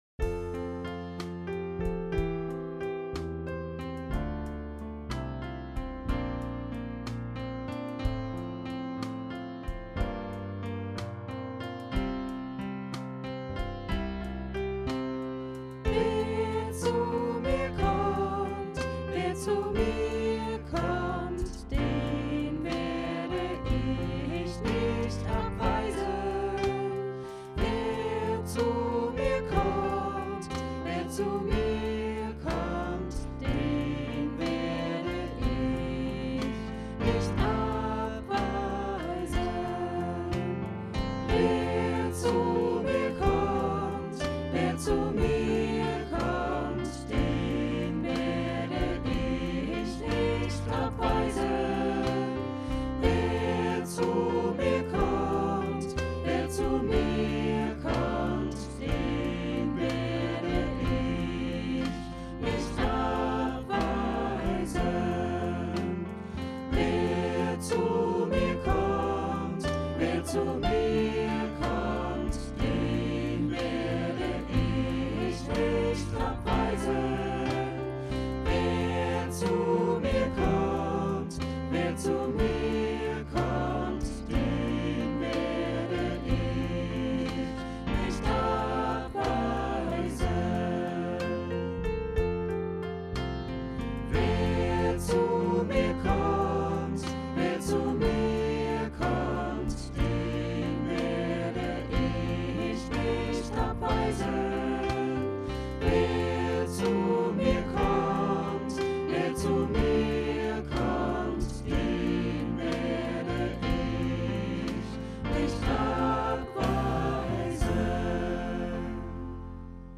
Vierstimmiger Satz